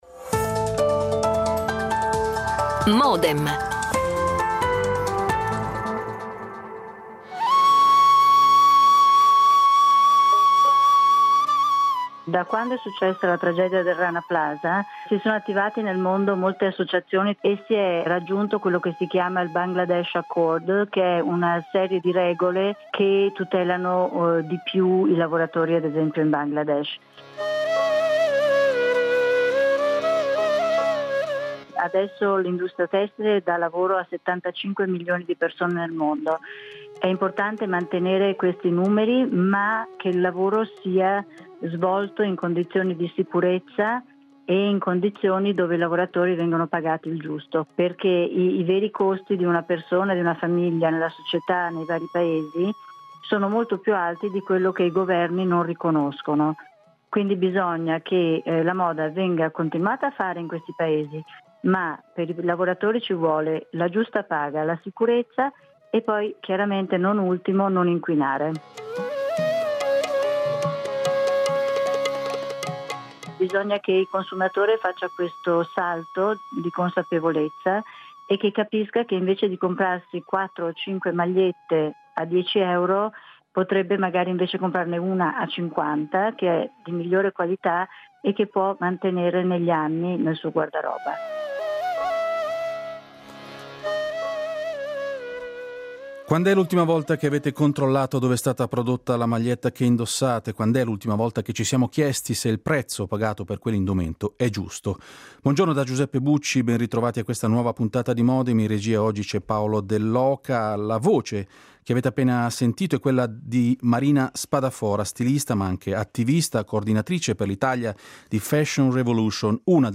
Interviste registrate
L'attualità approfondita, in diretta, tutte le mattine, da lunedì a venerdì